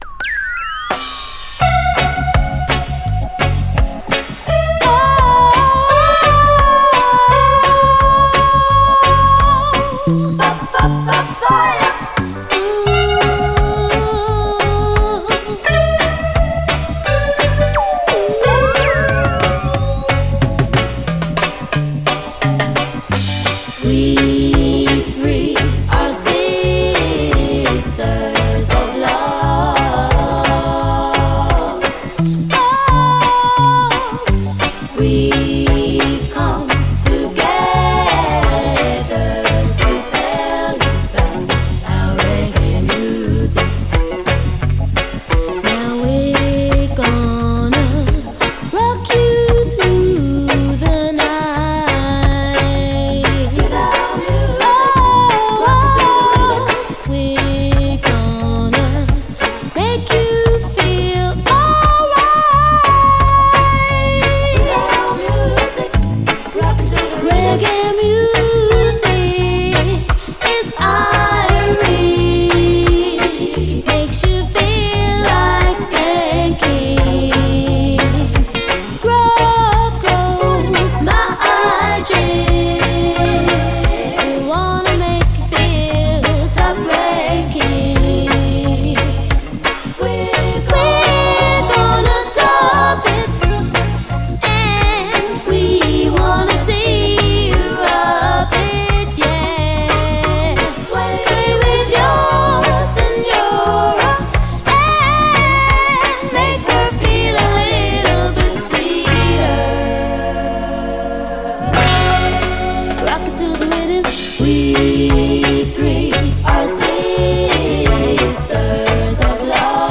盤面細かいスレ目立ちますが実際のノイズは少なく概ねプレイ良好。